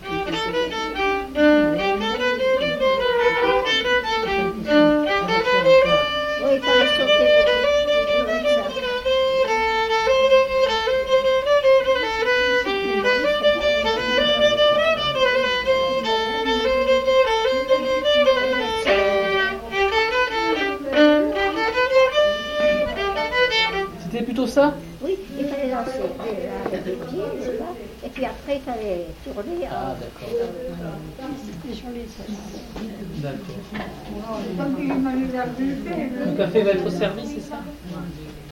Aire culturelle : Couserans
Lieu : Castillon-en-Couserans
Genre : morceau instrumental
Instrument de musique : violon
Danse : scottish